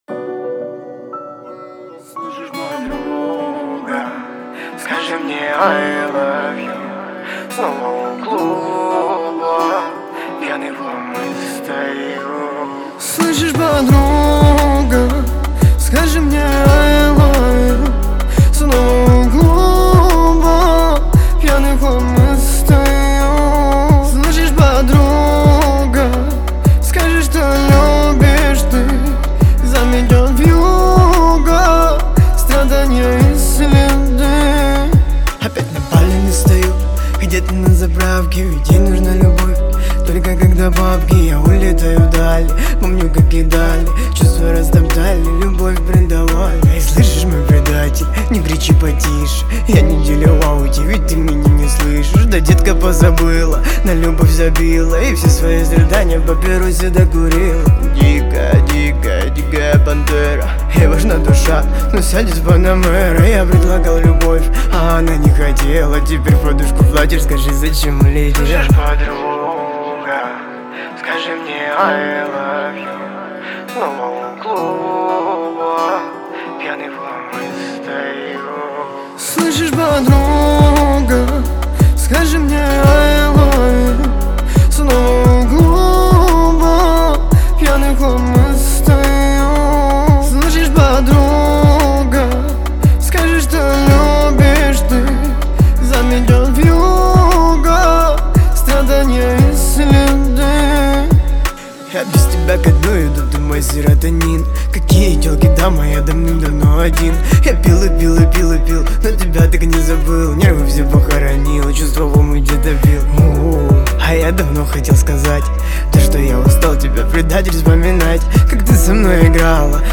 мелодичные вокалы